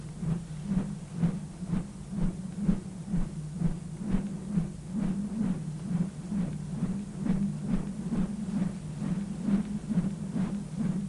描述：话筒前挥动绳子的声音，音调降低了
标签： 效果 直升机 处理
声道立体声